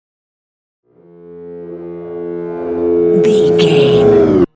Technologic riser human robot
Sound Effects
Atonal
bouncy
futuristic
tension